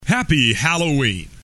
Tags: comedy announcer funny spoof crude radio